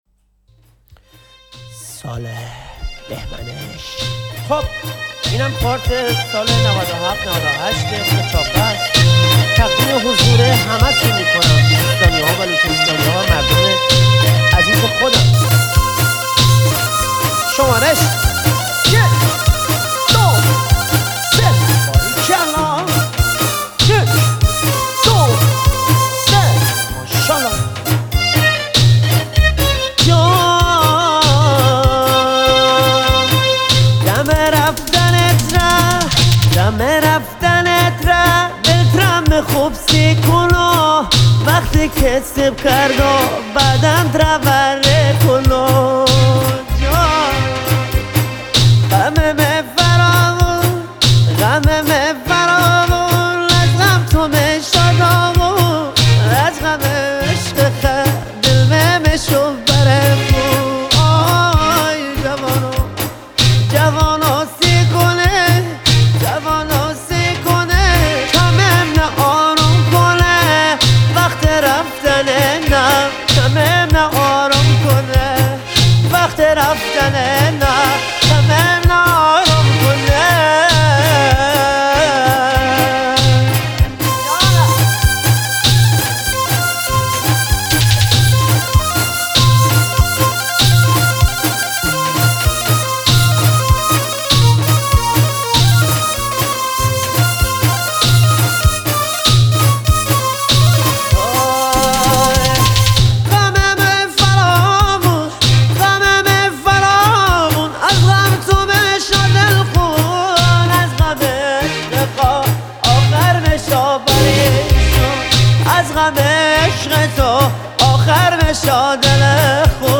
محلی